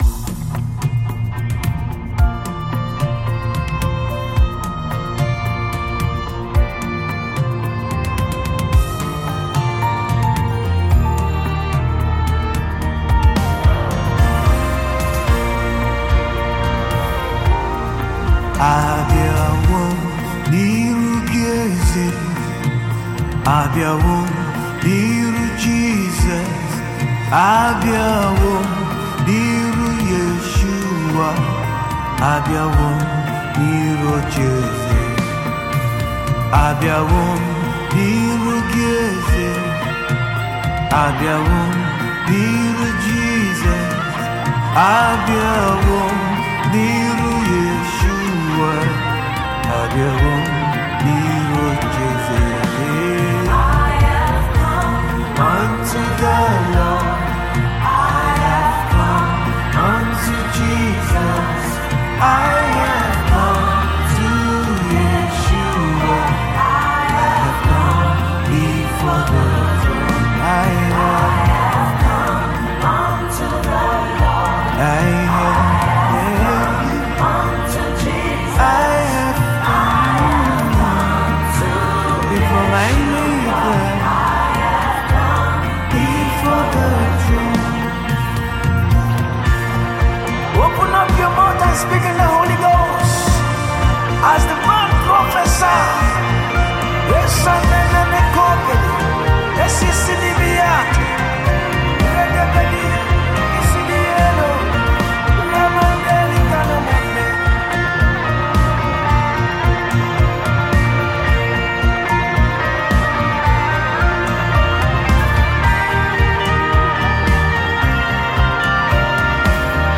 heartfelt worship song